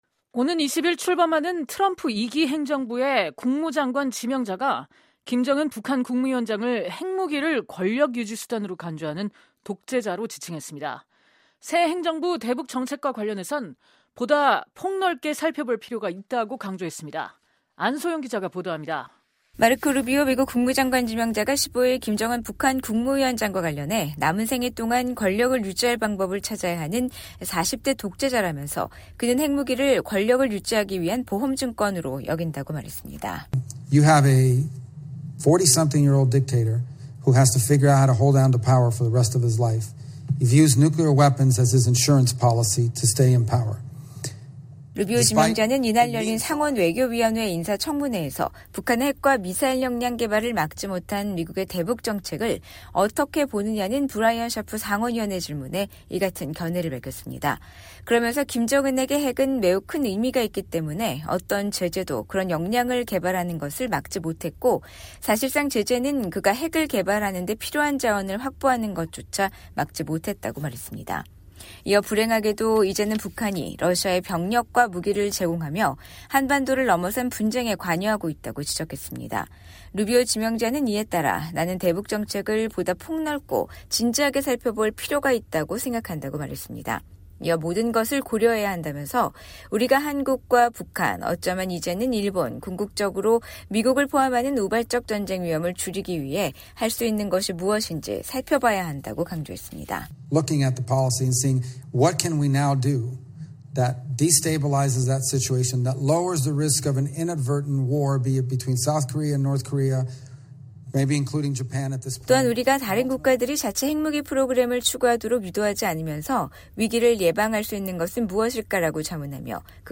마르코 루비오 미국 국무장관 지명자가 15일 상원외교원위원회 인사청문회에서 발언하고 있다.